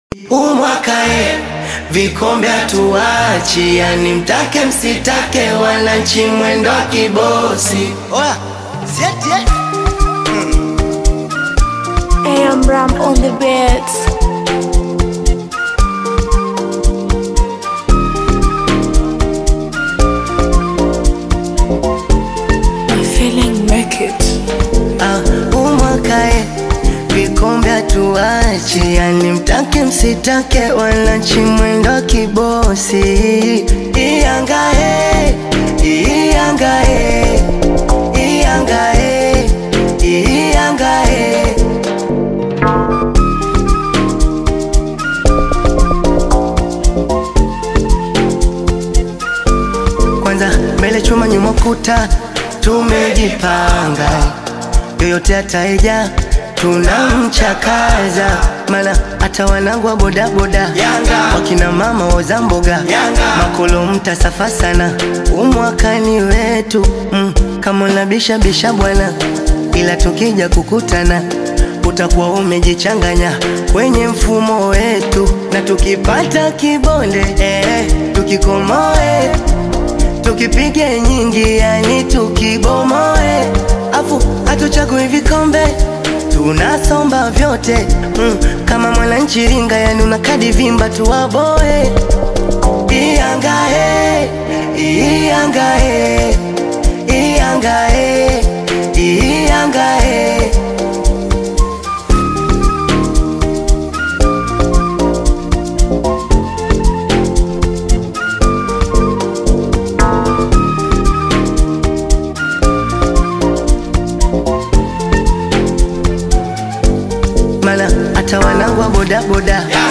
energetic track